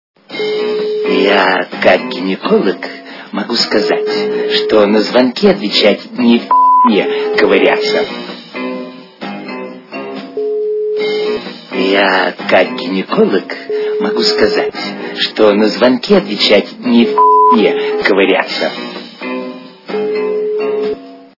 » Звуки » Люди фразы » Звук - Я как гинеколог, могу сказать-на звонки отвечать, не в пи..е ковыряться
При прослушивании Звук - Я как гинеколог, могу сказать-на звонки отвечать, не в пи..е ковыряться качество понижено и присутствуют гудки.